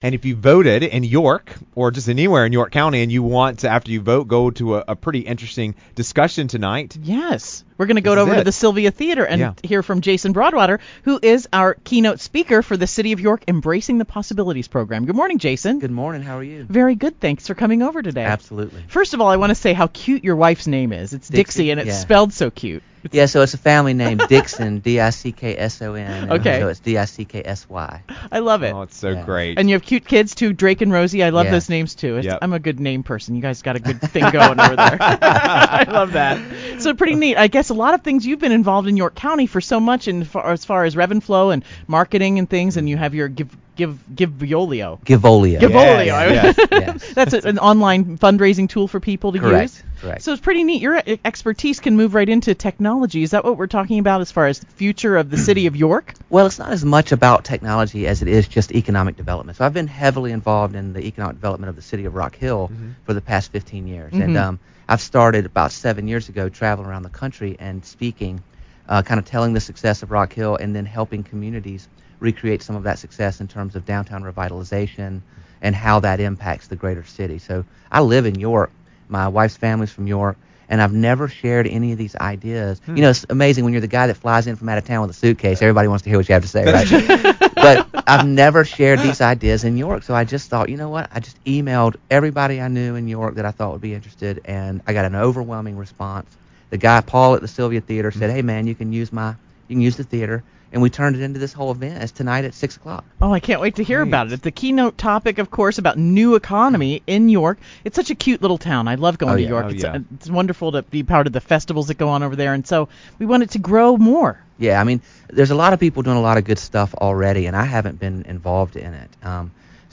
City of York Presentation at The Sylvia Theater